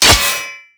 metal3.wav